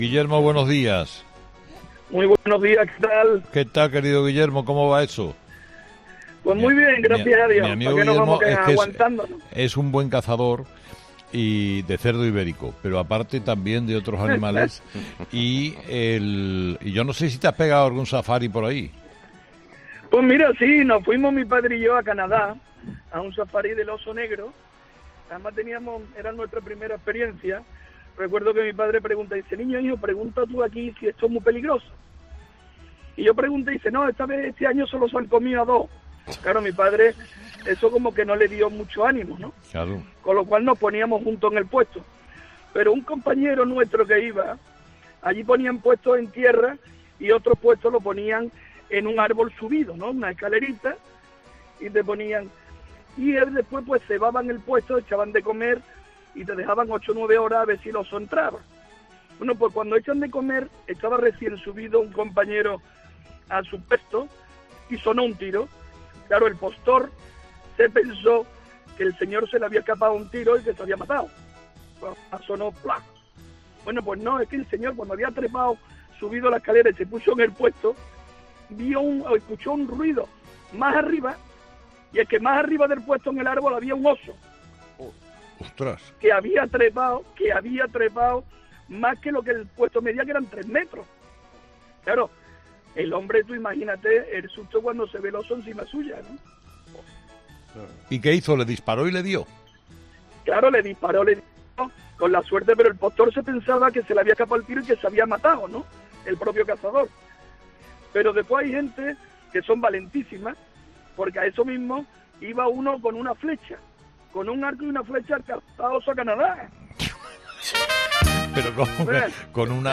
Los oyentes de Carlos Herrera han relatado las historias más graciosas que han vivido en sus safaris por el mundo